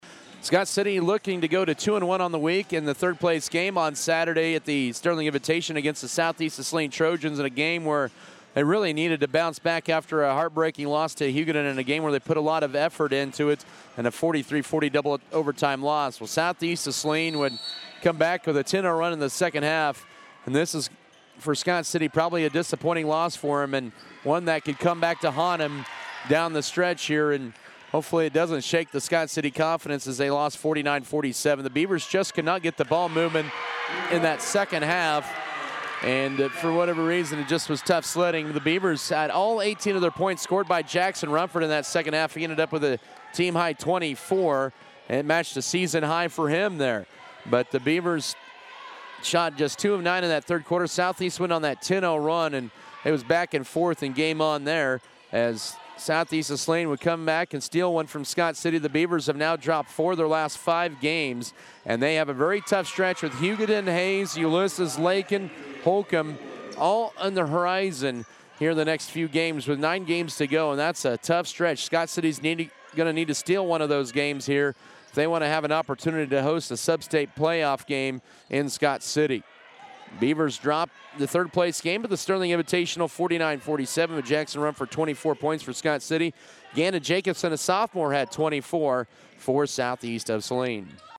Boys Audio Recap